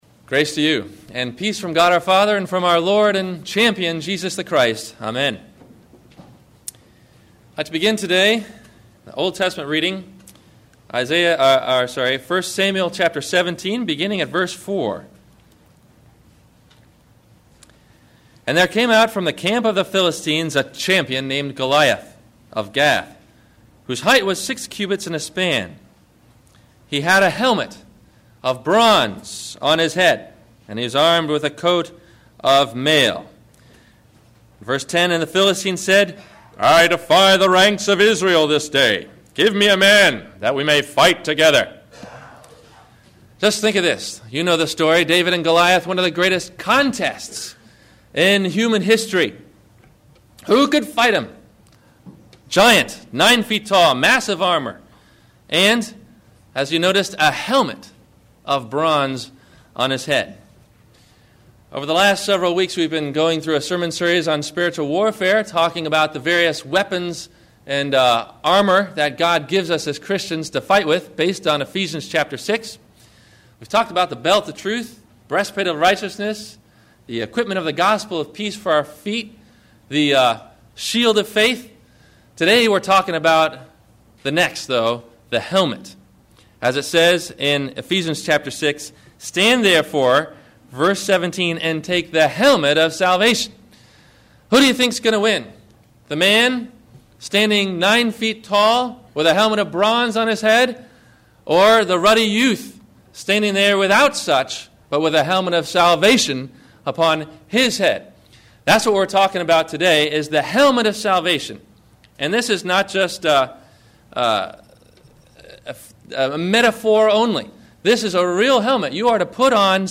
The Helmet of Salvation – Sermon – October 19 2008